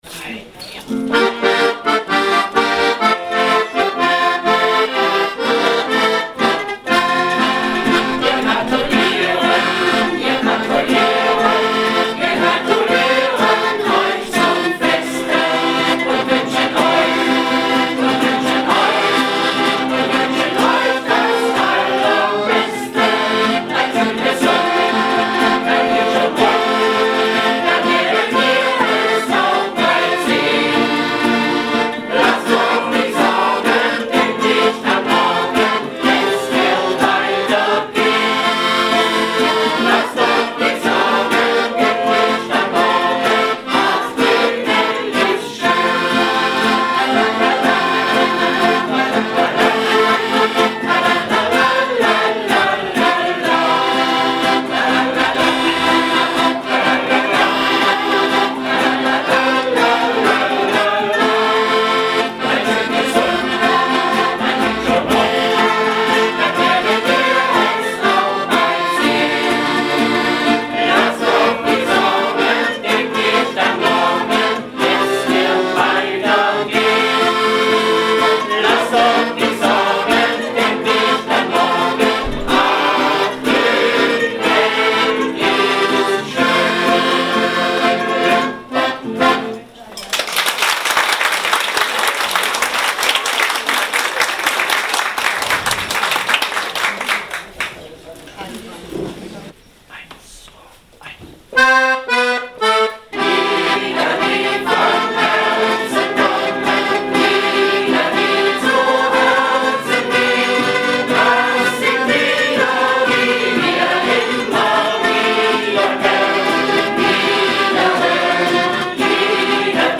Ausschnitte aus einem Konzert
Mundharmonika